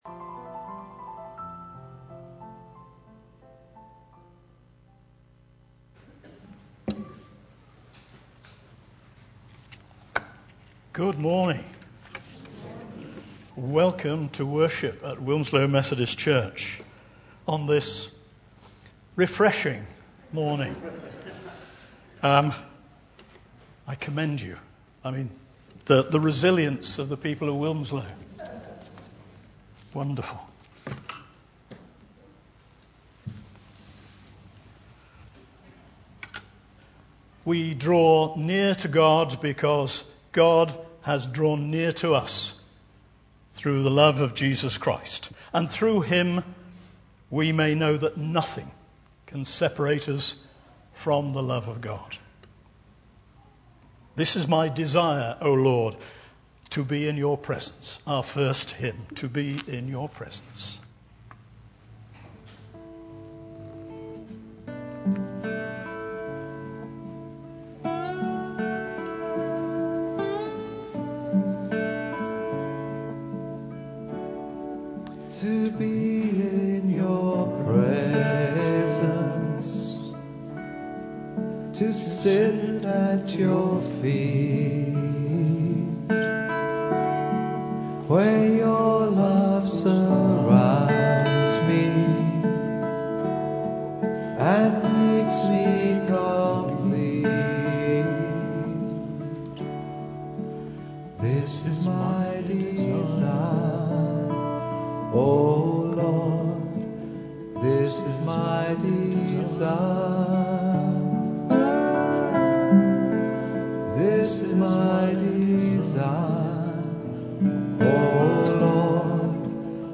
2021-08-08 Morning Worship
Genre: Speech.